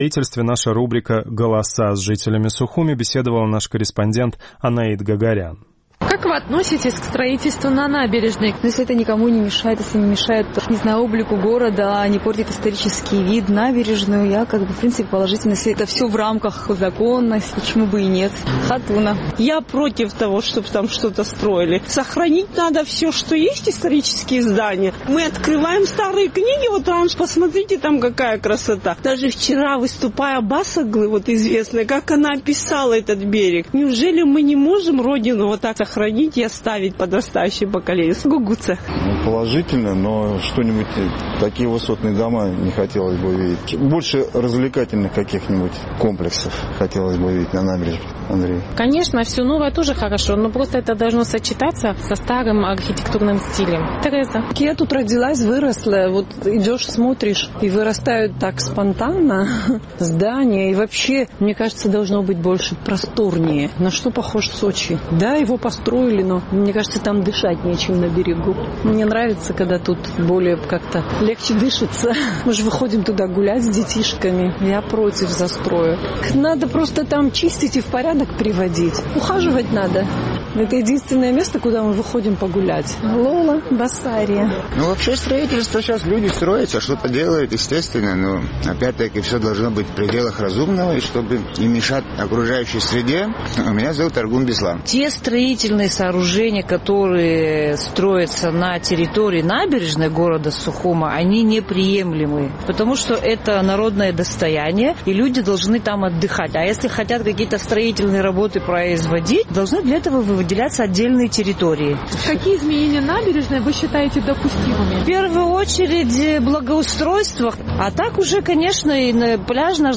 В Абхазии разгорается скандал с самостроем на столичной набережной. Наш сухумский корреспондент поинтересовалась мнением местных жителей по этому поводу.